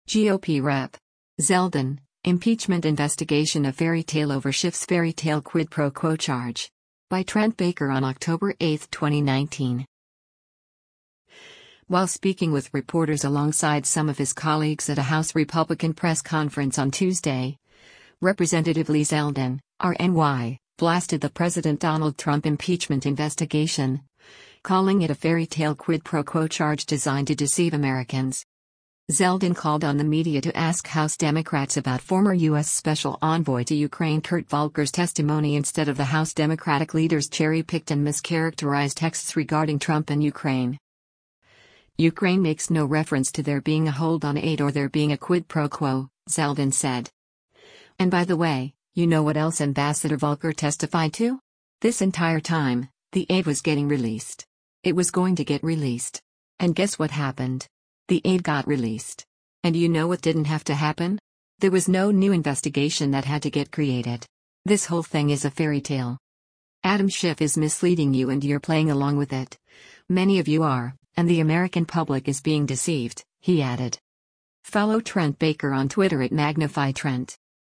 While speaking with reporters alongside some of his colleagues at a House Republican press conference on Tuesday, Rep. Lee Zeldin (R-NY) blasted the President Donald Trump impeachment investigation, calling it a “fairytale quid pro quo charge” designed to deceive Americans.